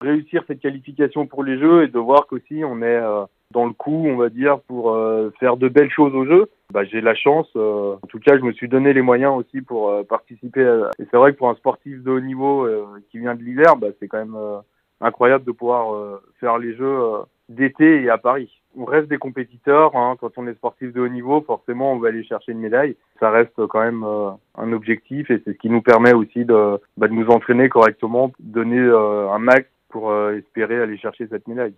Le Bornandin Benjamin Daviet nous parle de cet incroyable projet :